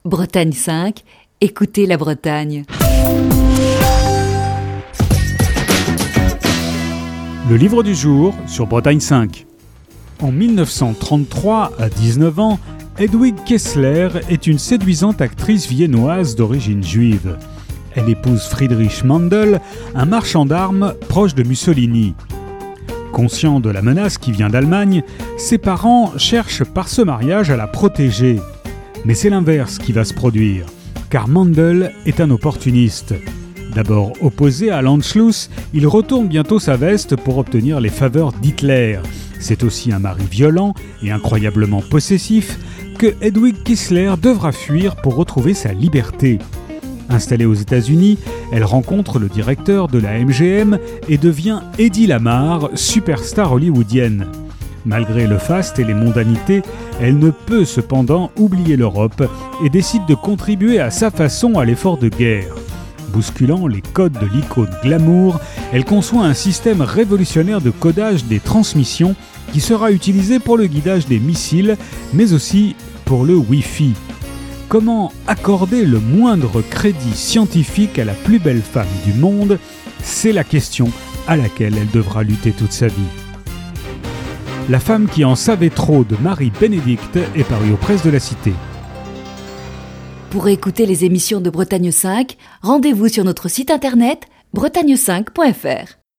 Chronique du 29 octobre 2020.